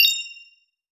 Notification 4.wav